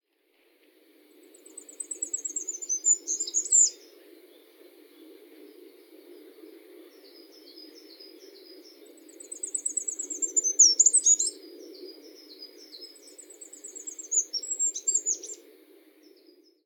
２　キクイタダキ（菊戴）スズメ目キクイタダキ科キクイタダキ属　大きさ：10cm
さえずりを文字で表すのは難しく、『日本の野鳥　さえずり・地鳴き図鑑』（メイツ出版）植田睦之（監修）によると「細い声でツピチツピチと次第に早く高く続けて、チリリーリーと下がる声で鳴く」とある。
【録音②】 キクイタダキ